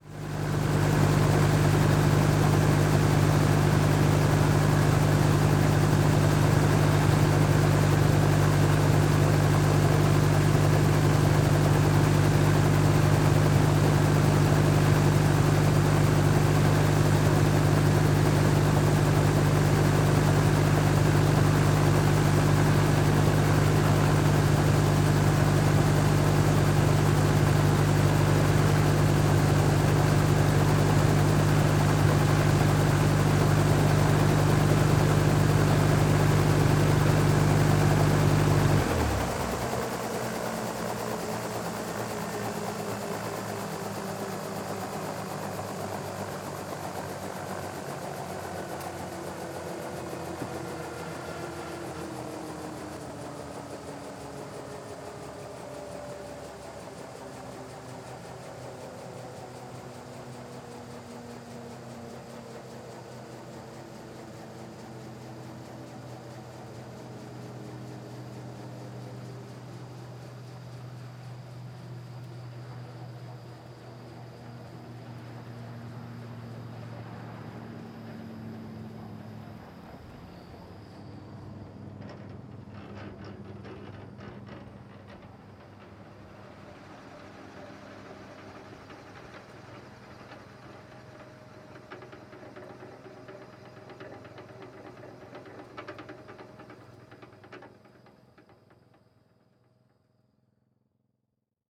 transport
Helicopter Idle Shut Off Wind Down